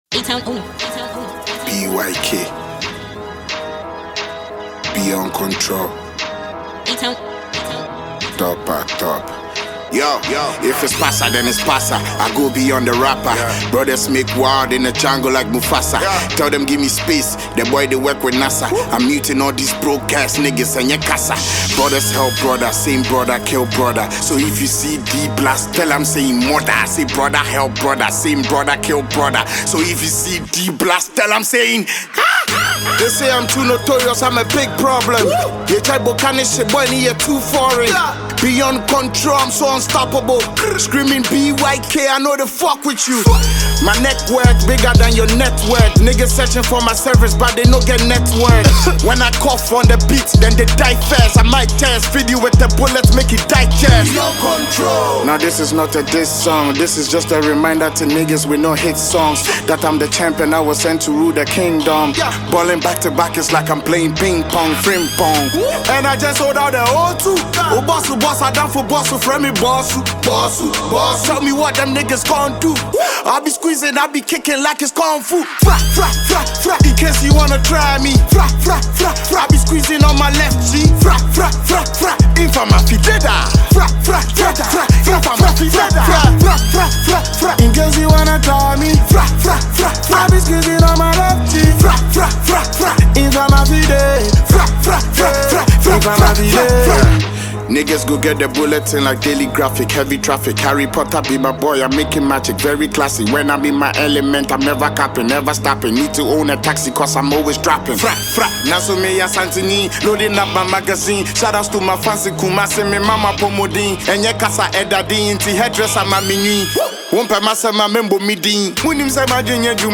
” a legendary Ghanaian rap prodigy and songwriter